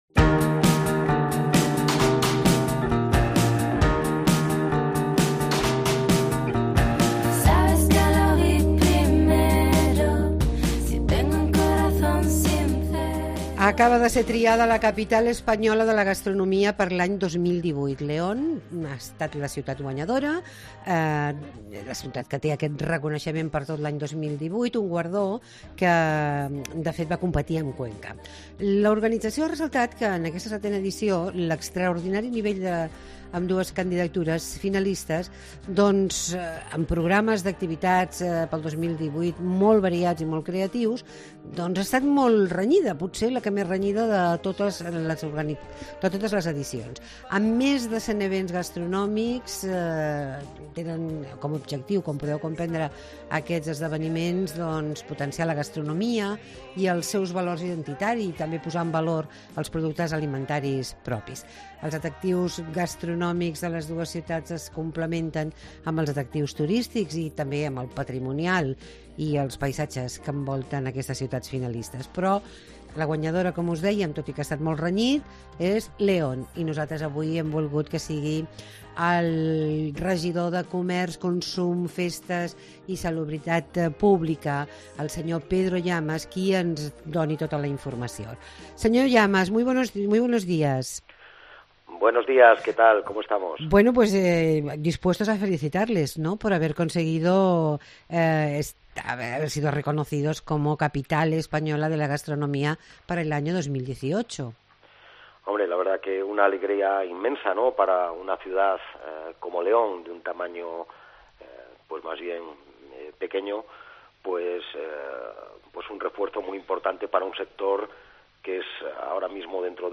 Entrevista a Pedro Llamas, concejal del Ayuntamiento de León